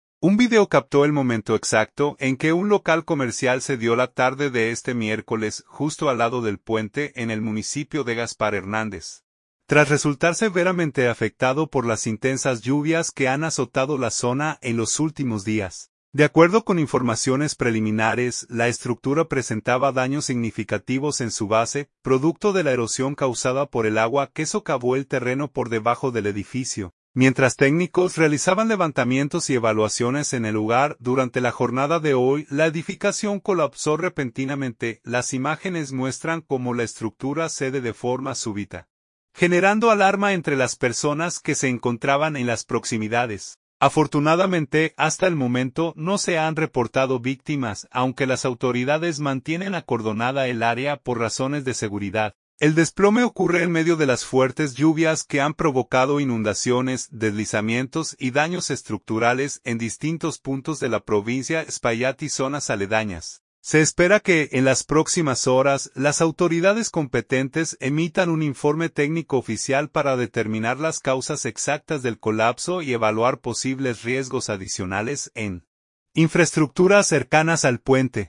Impactante: cámara registra el momento en que edificio cede junto al puente de Gaspar Hernández
Gaspar Hernández, RD.– Un video captó el momento exacto en que un local comercial cedió la tarde de este miércoles, justo al lado del puente en el municipio de Gaspar Hernández, tras resultar severamente afectado por las intensas lluvias que han azotado la zona en los últimos días.